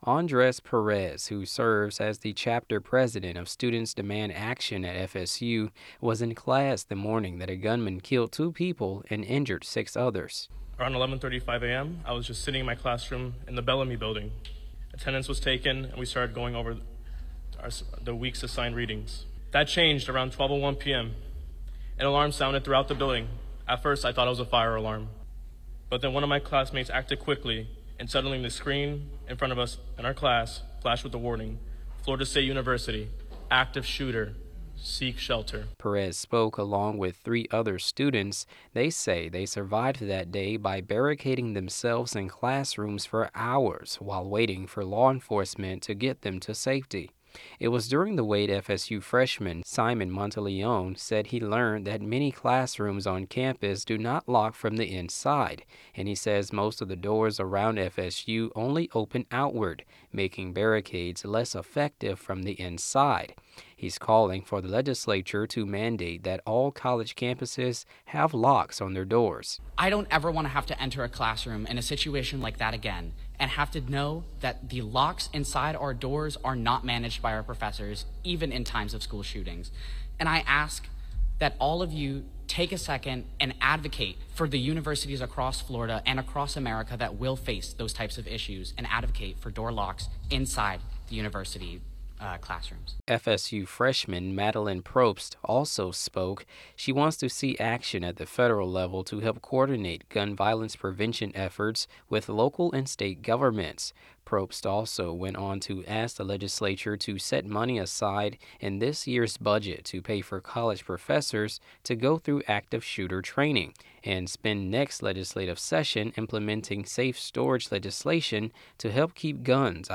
• Florida State University students were at the Capitol on Tuesday sharing their experiences from last week’s deadly school shooting.
BROADCAST TRANSCRIPT: